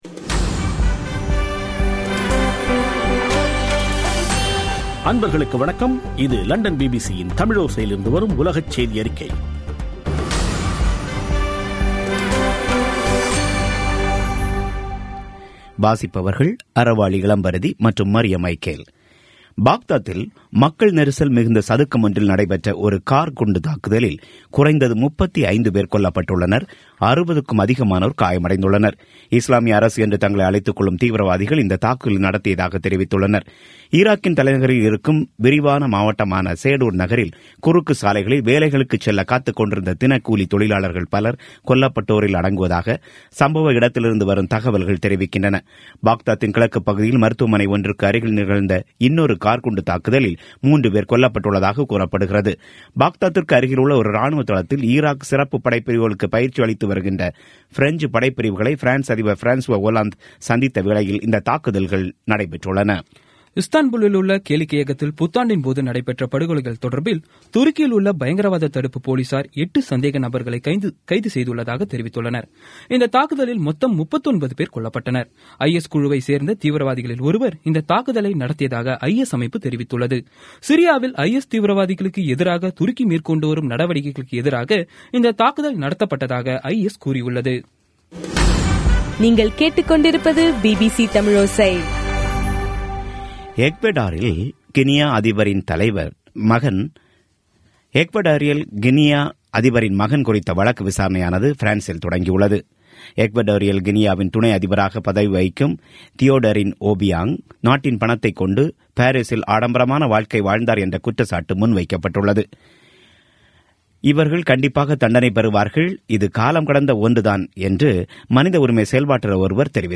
பி பி சி தமிழோசை செய்தியறிக்கை (02/01/17)